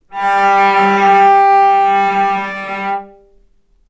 Strings / cello / sul-ponticello
vc_sp-G3-ff.AIF